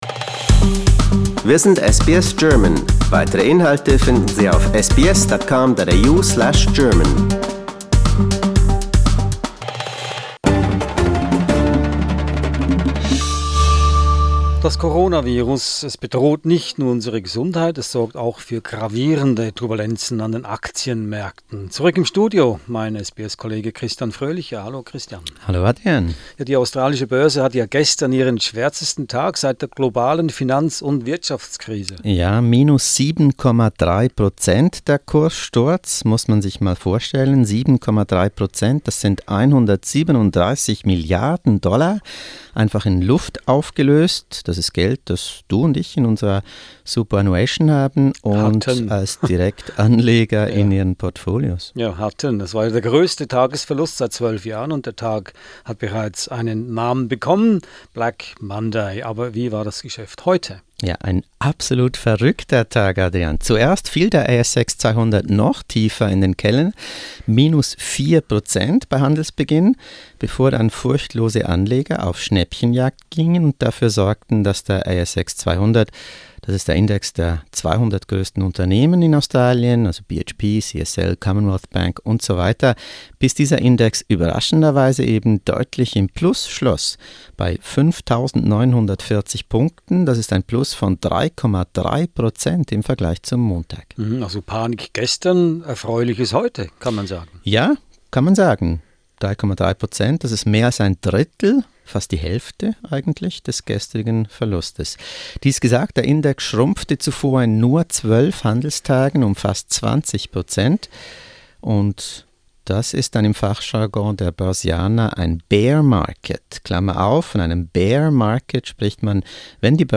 Es sorgt auch für gravierende Turbulenzen an den Aktienmärkten. Ein Studiogespräch, 24 Stunden nach dem Black Monday in Australien.